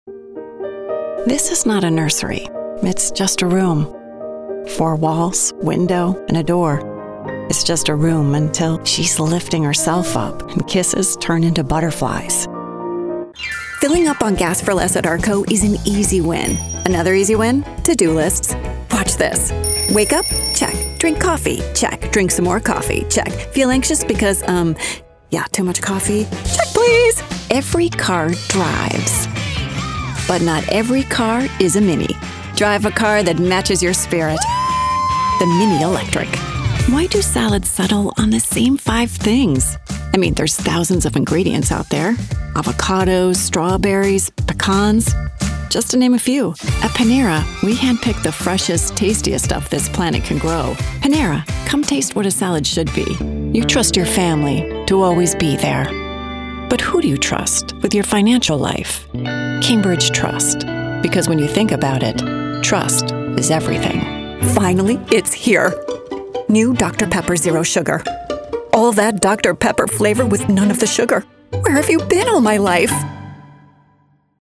Warm Conversational Trustworthy